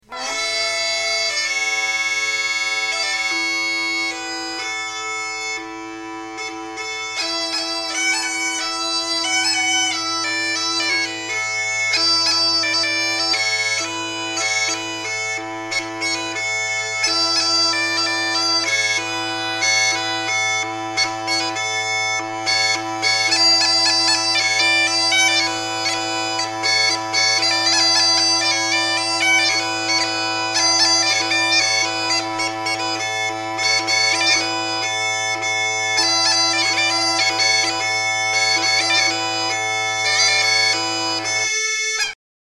Gajdy
Vyrába väčšinou trojhlasné gajdy podpolianskeho typu v ladení A a F a typu z okolia pohronského inovca v ladení B.
gajdy1.mp3